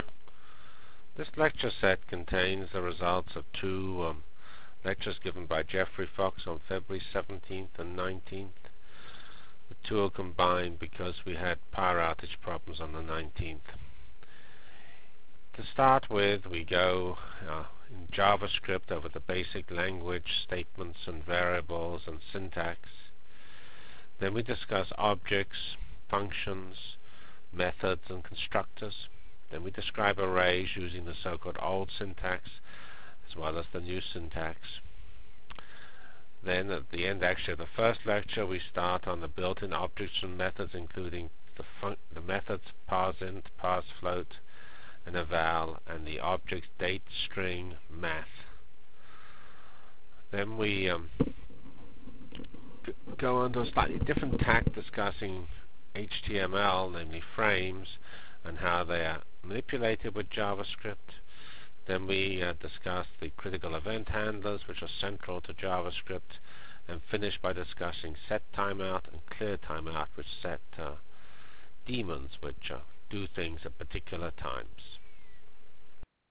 Foil 1 CPS 616 JavaScript Lectures